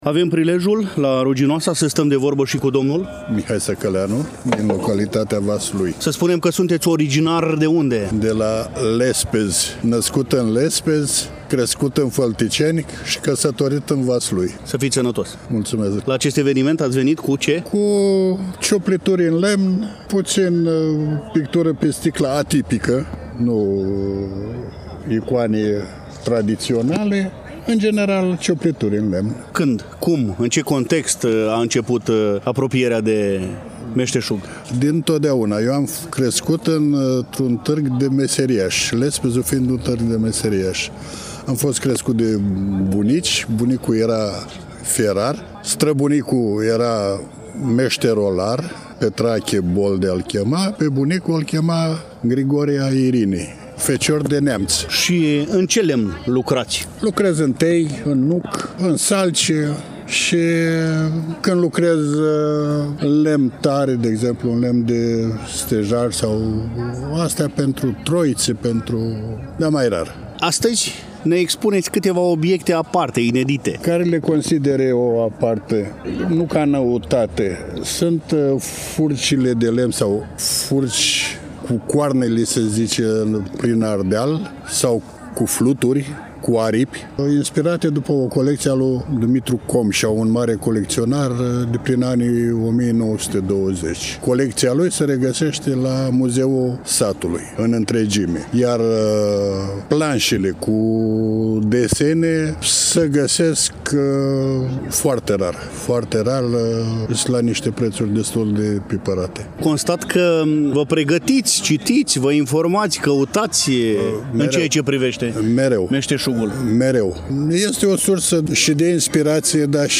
Vă reamintim că relatăm din Ruginoasa, Iași, acolo unde, nu demult, s-a desfășurat o frumoasă și unică sărbătoare dedicată păstrării, conservării și promovării tradițiilor.
În cele ce urmează, ne îndreptăm pașii spre Târgul Meșterilor Populari, manifestare care a avut loc pe Aleea  Muzeului Memorial „Alexandru Ioan Cuza”.